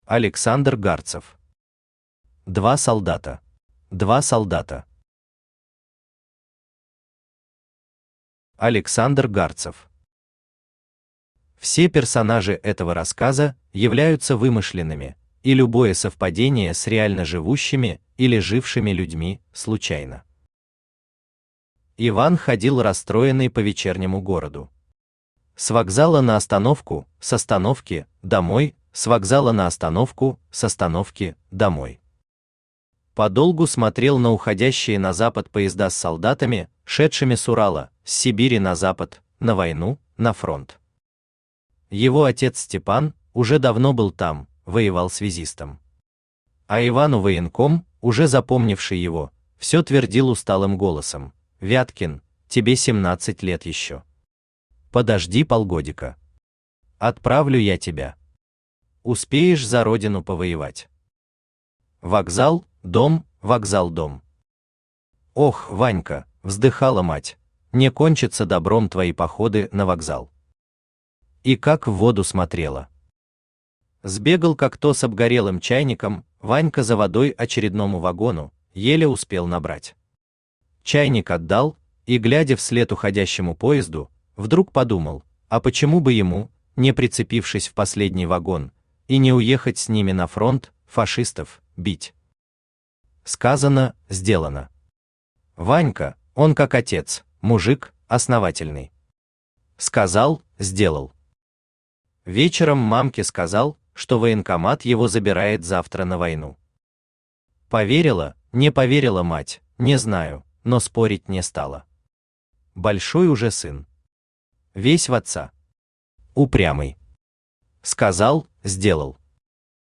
Аудиокнига Два солдата | Библиотека аудиокниг
Aудиокнига Два солдата Автор Александр Гарцев Читает аудиокнигу Авточтец ЛитРес.